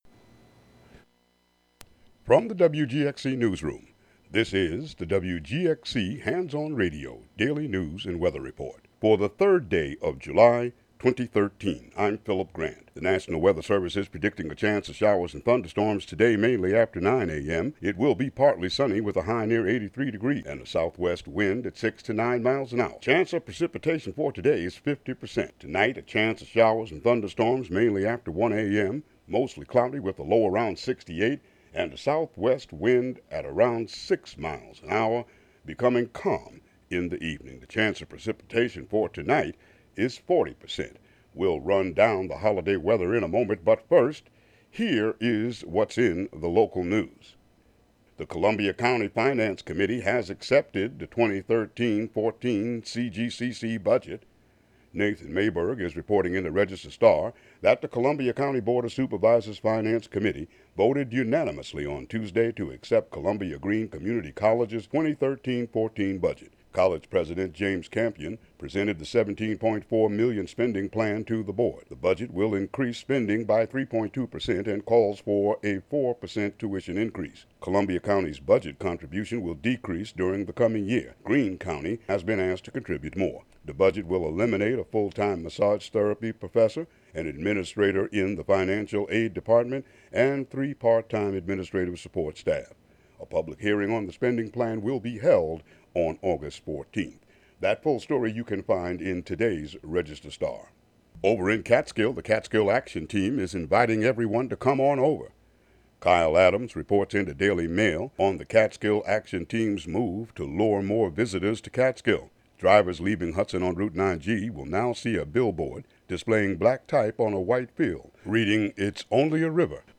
Local news and weather for Wednesday, July 3, 2013.